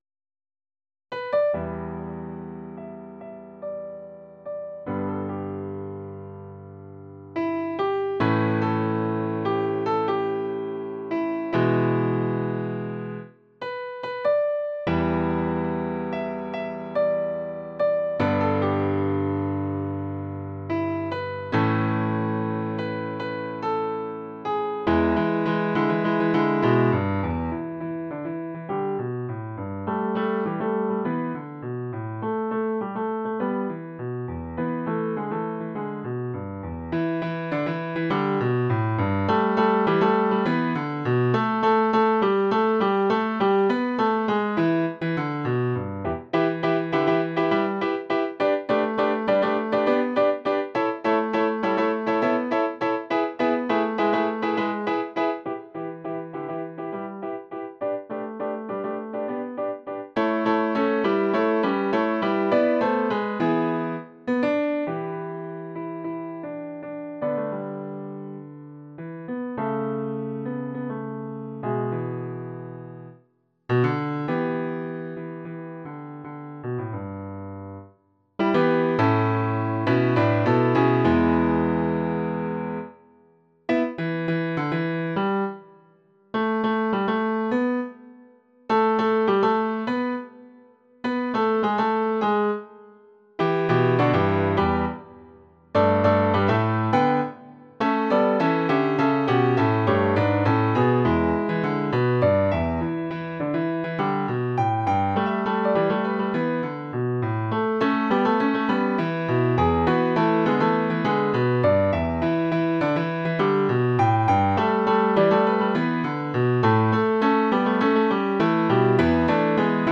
TalkingToThePeople-choral-Em-MIDI-Rendering.mp3
TalkingToThePeople-choral-Em-condensedScore.mp3